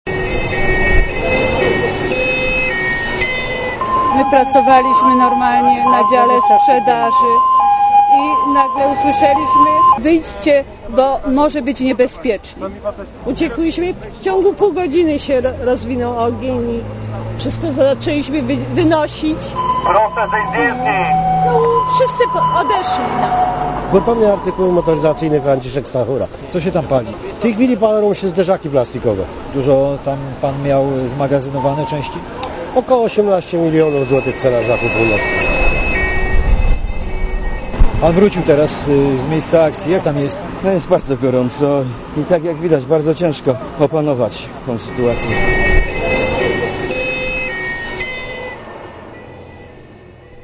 pozar.mp3